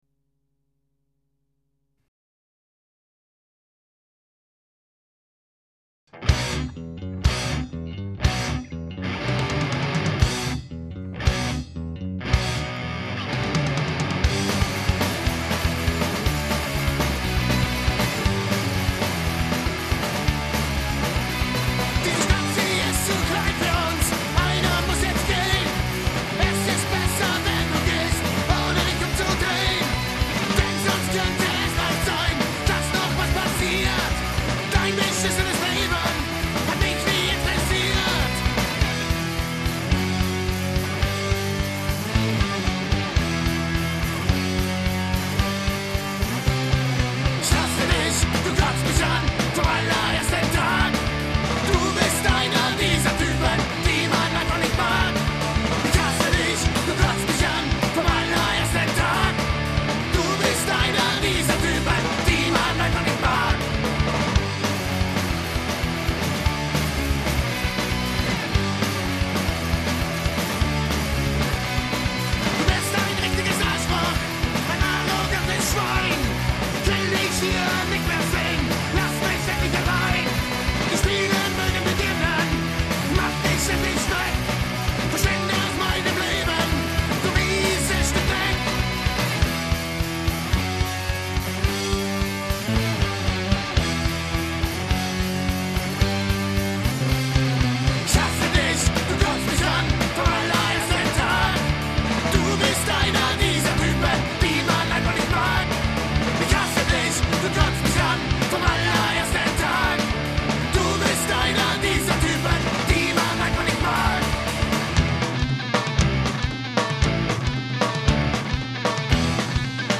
Hört selbst in die Rough-Mixe des gleichnamigen Albums rein